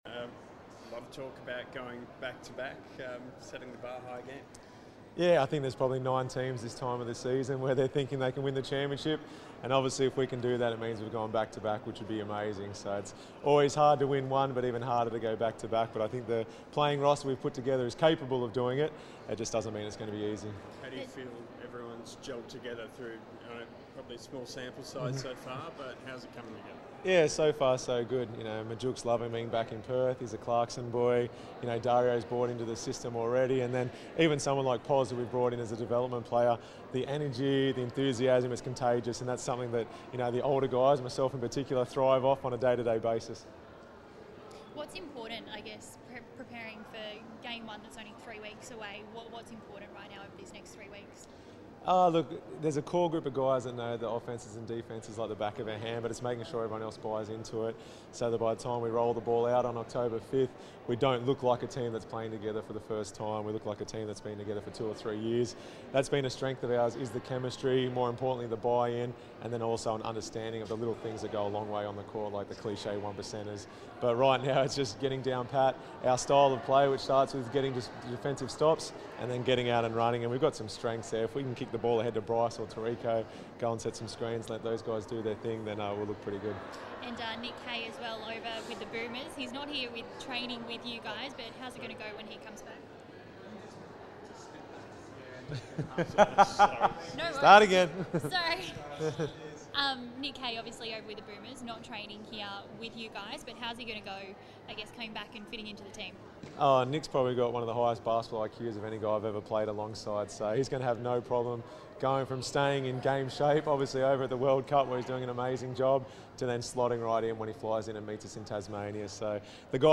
Damian Martin Press Conference - 13 September 2019